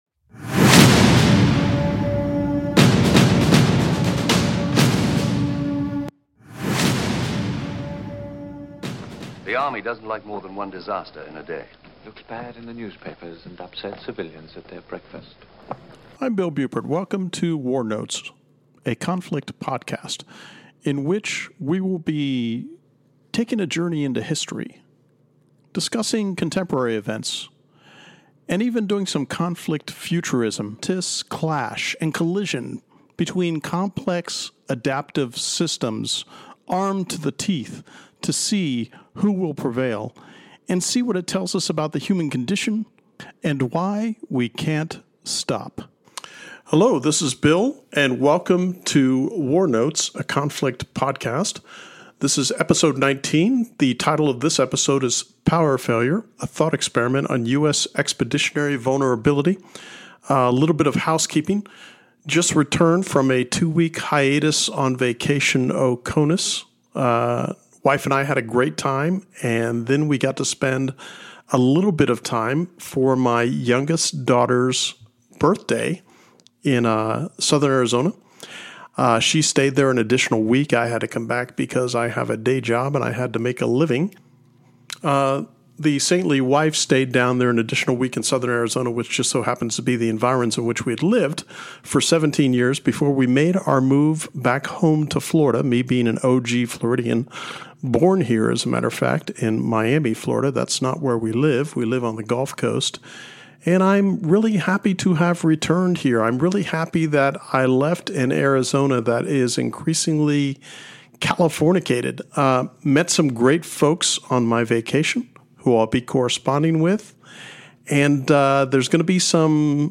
Effective June 2025, I did a major improved revision on the sound quality for all my WarNotes episodes retroactively thanks to the technology at Podsworth.